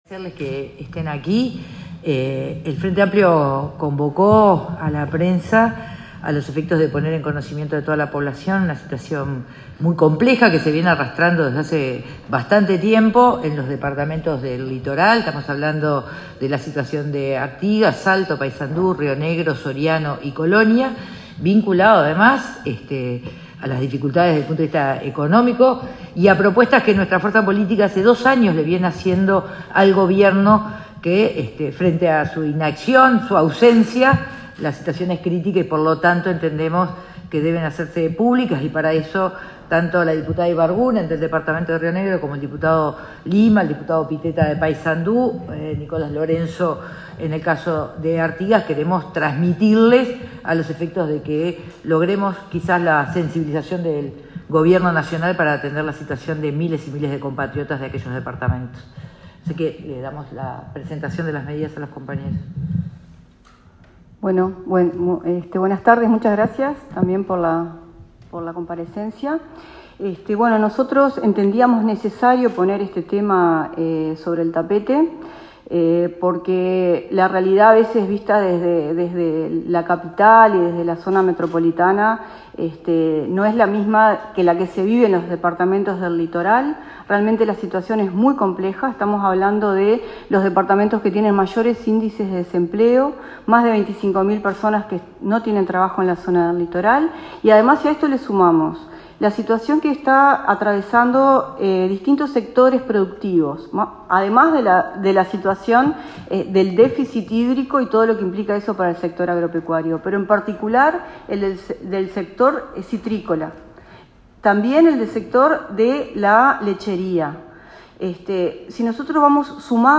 Conferencia_diputad_FA-2.mp3